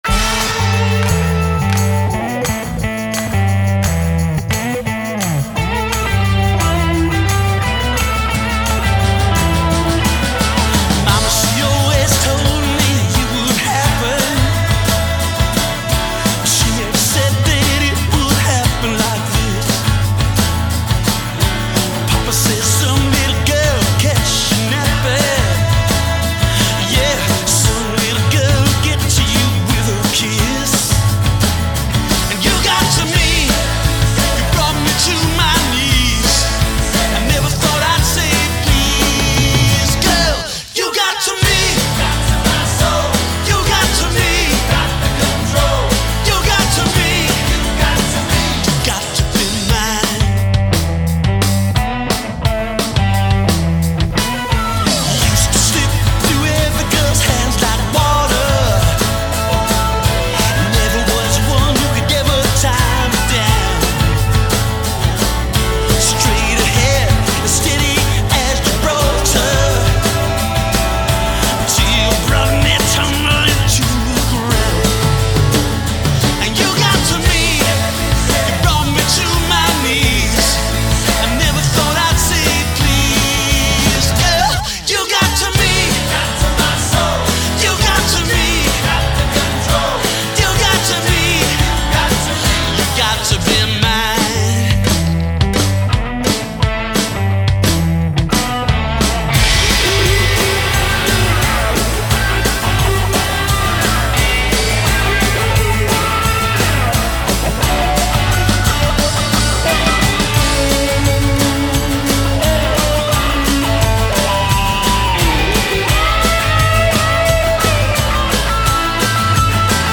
Ld Vocal, Guitar
Bass
Drums